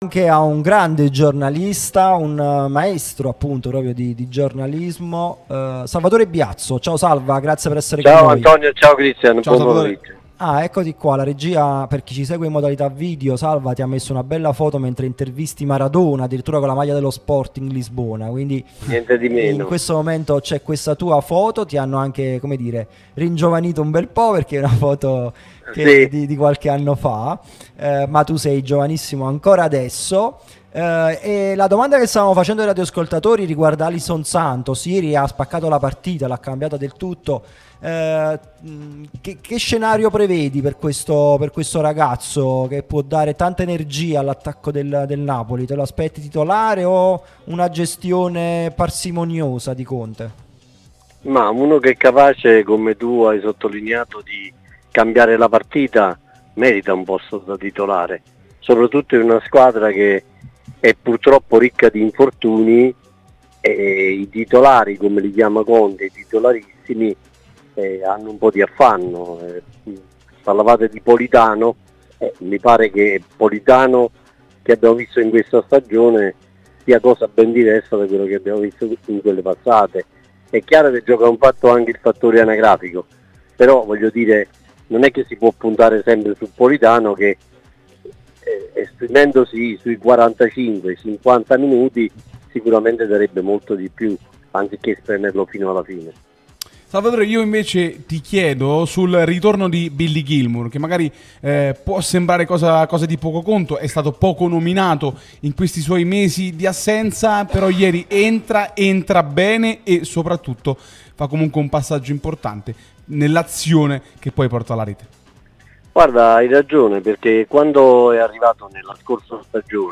Radio Tutto Napoli